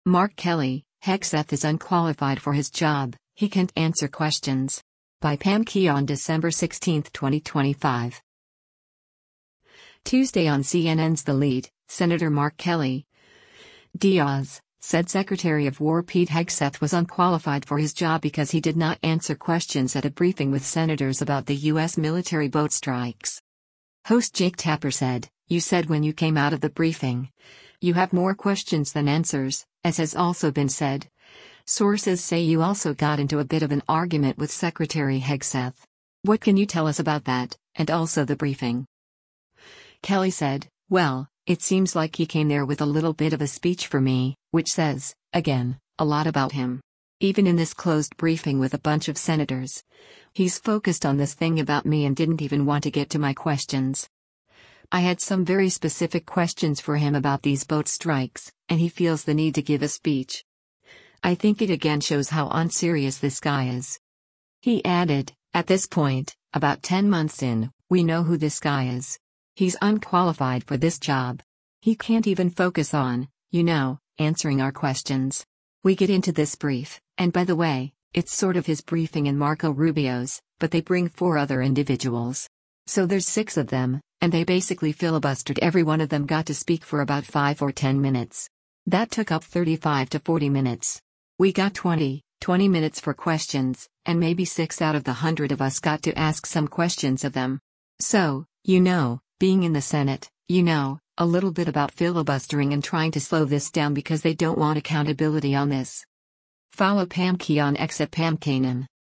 Tuesday on CNN’s “The Lead,” Sen. Mark Kelly (D-AZ) said Secretary of War Pete Hegseth was “unqualified” for his job because he did not answer questions at a briefing with senators about the U.S. military boat strikes.